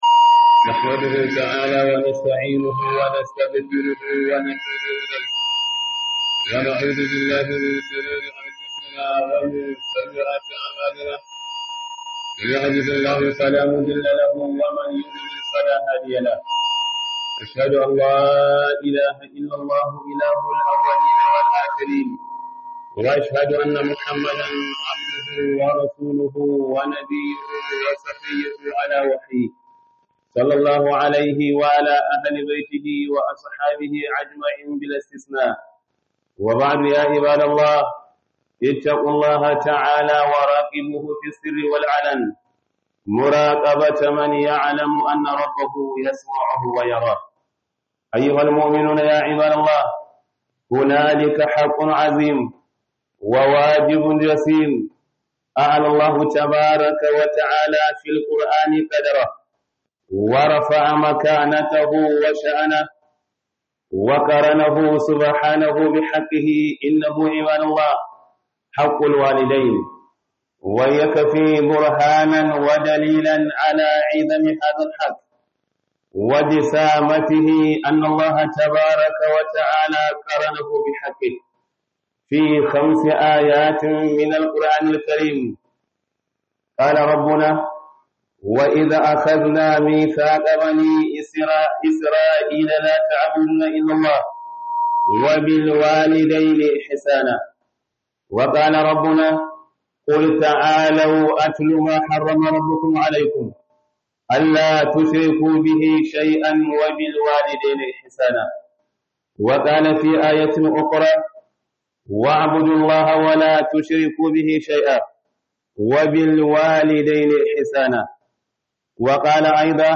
KHUDUBAR JUMA'A